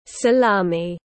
Xúc xích Ý tiếng anh gọi là salami, phiên âm tiếng anh đọc là /səˈlɑː.mi/
Salami /səˈlɑː.mi/